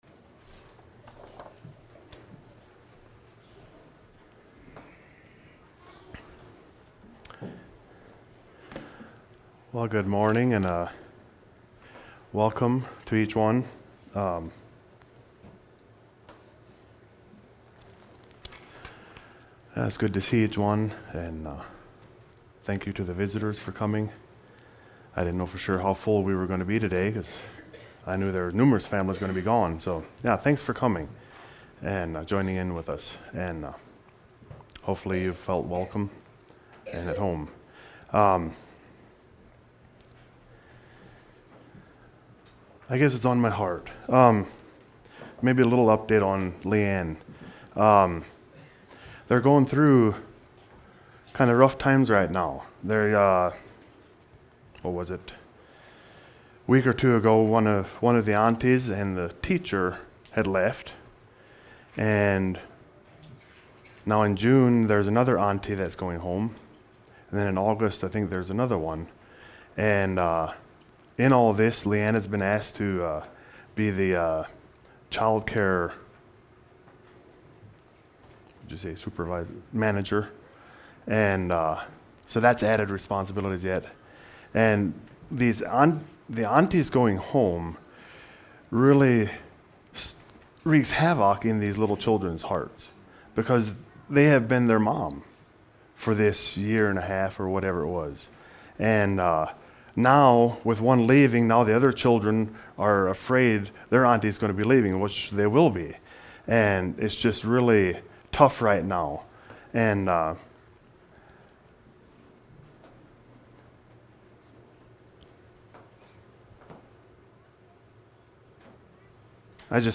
Sermons Sermon on the Mount